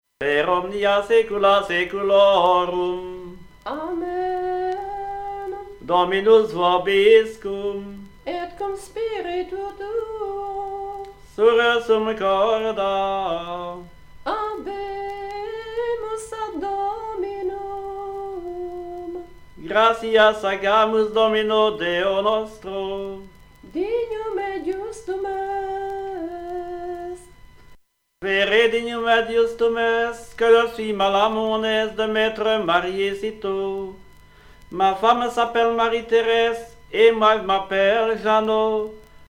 circonstance : fiançaille, noce